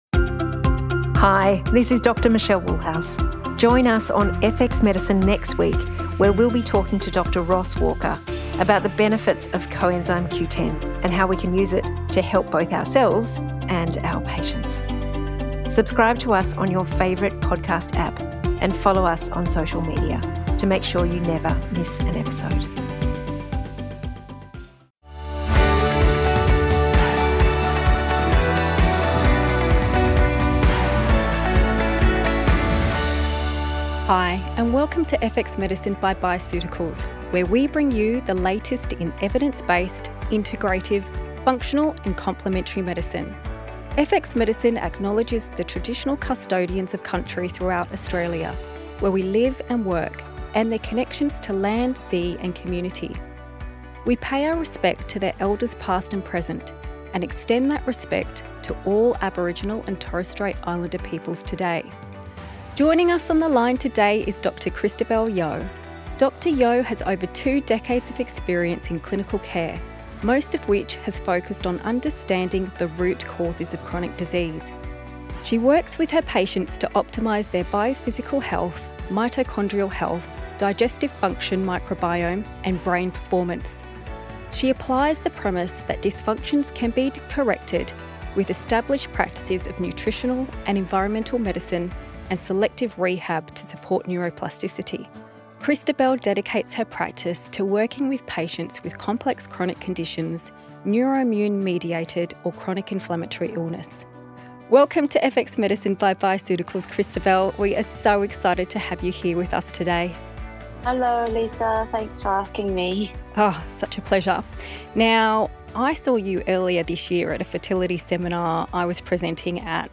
Warm, engaging, and invested in her subject